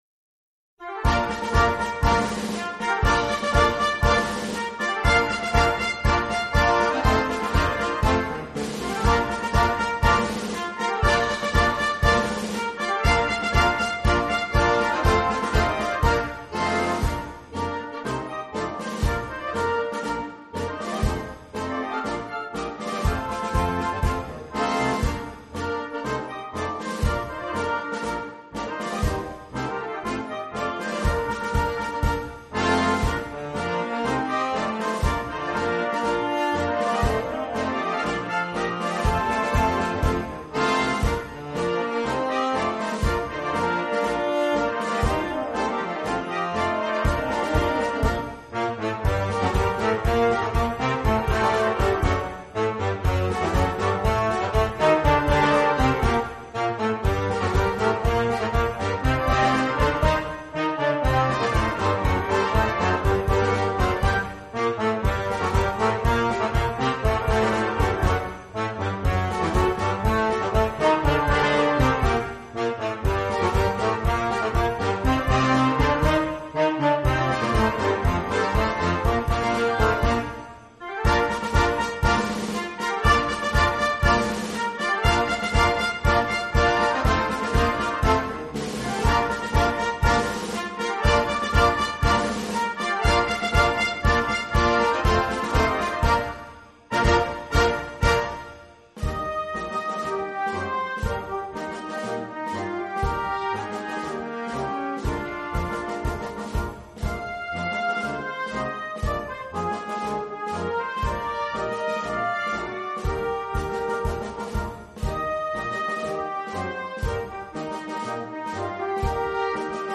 Collection : Harmonie (Marches)
Marche-parade pour
harmonie ou fanfare, avec
tambours et clairons ad lib.